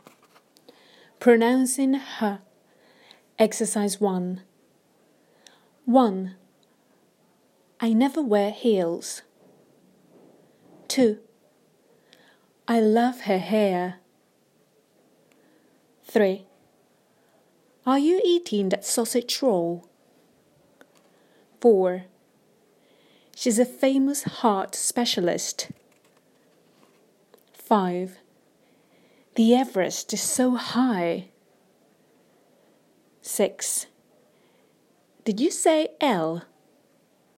Pronouncing /h/